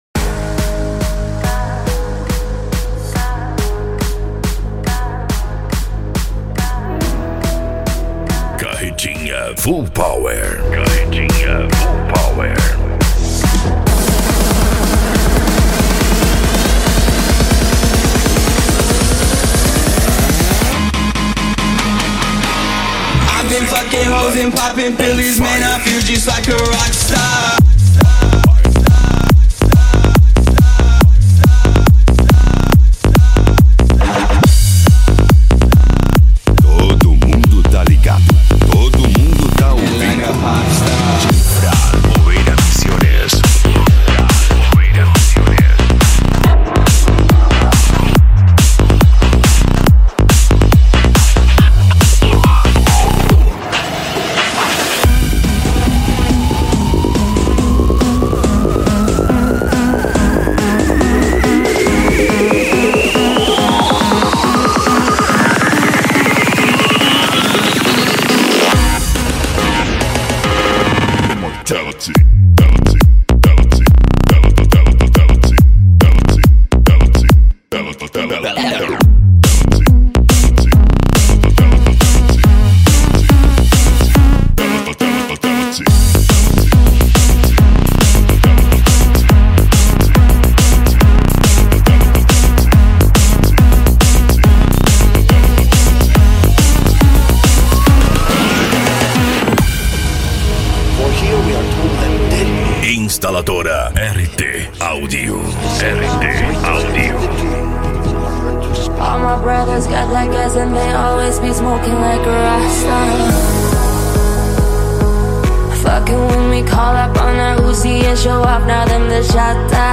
Bass
Remix
Musica Electronica